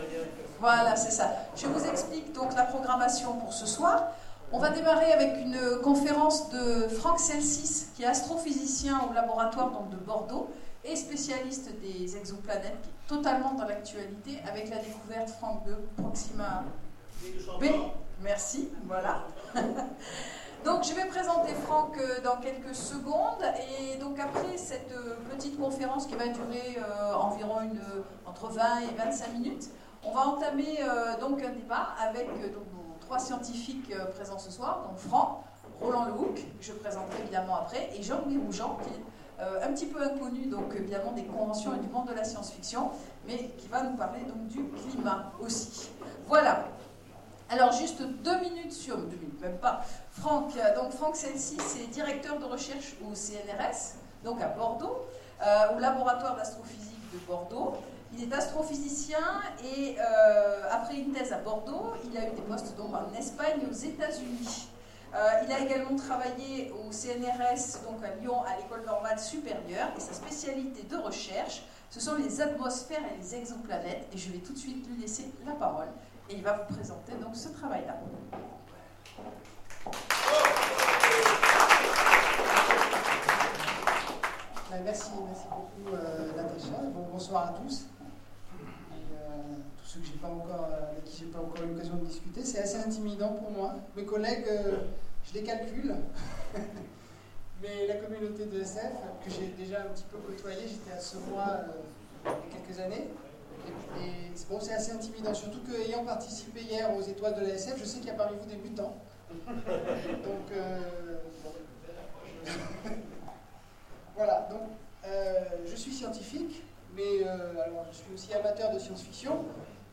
Convention Science fiction 2016 Gradignan Conférence SF et vie réelle